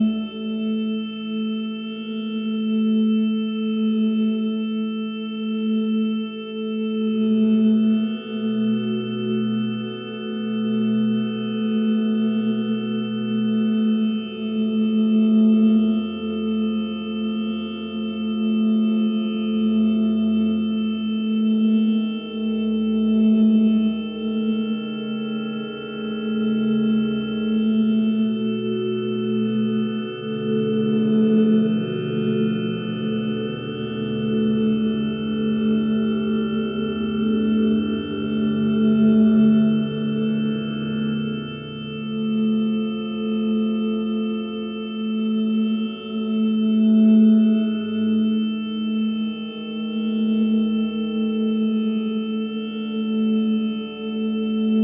Add ambient music which changes based on pill level